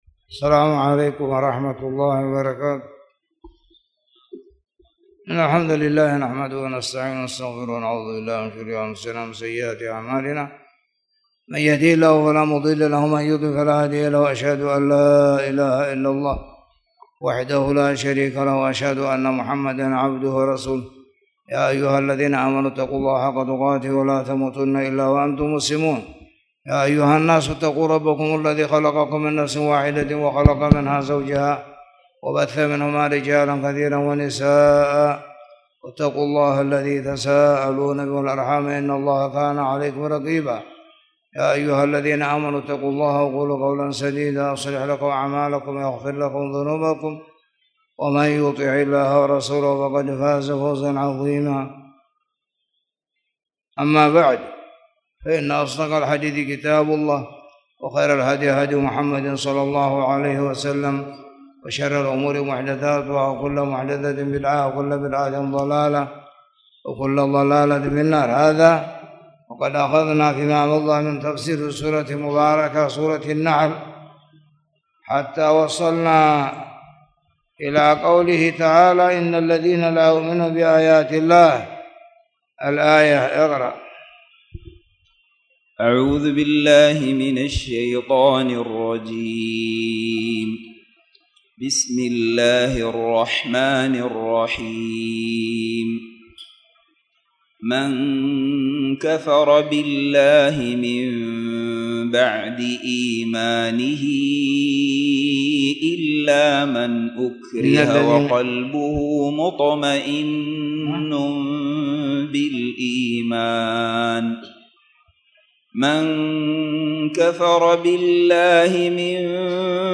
تاريخ النشر ٩ ربيع الأول ١٤٣٨ هـ المكان: المسجد الحرام الشيخ